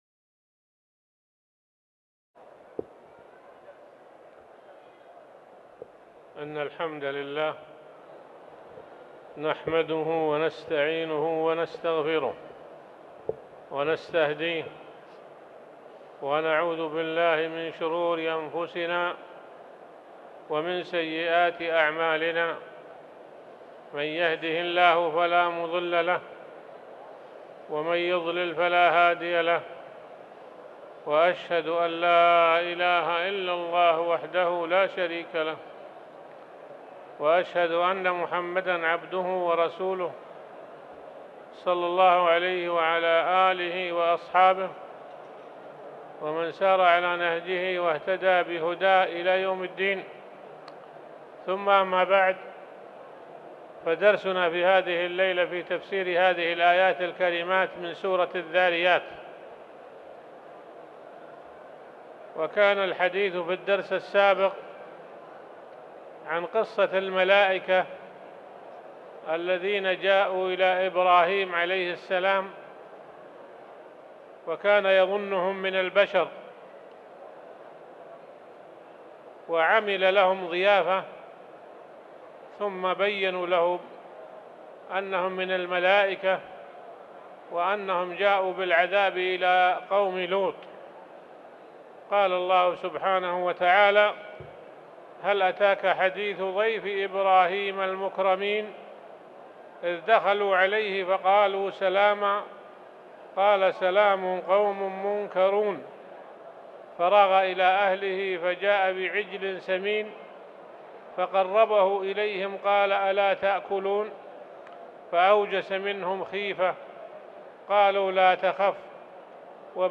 تاريخ النشر ١٠ ربيع الثاني ١٤٤٠ هـ المكان: المسجد الحرام الشيخ